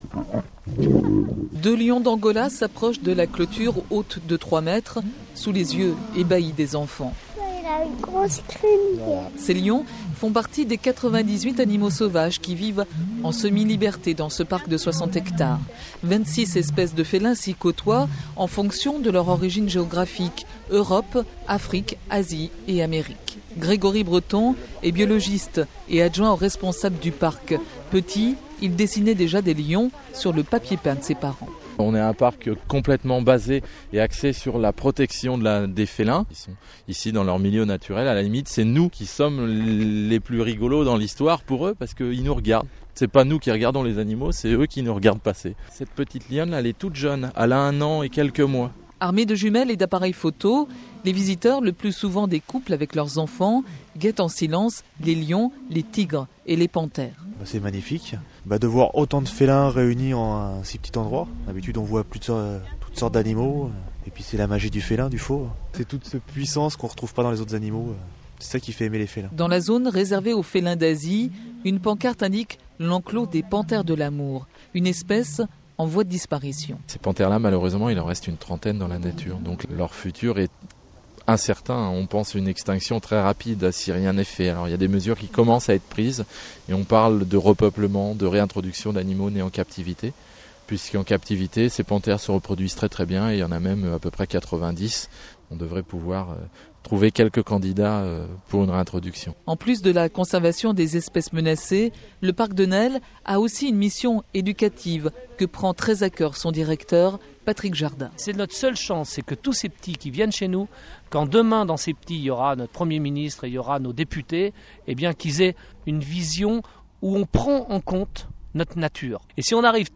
ITW_France_Info_2008.mp3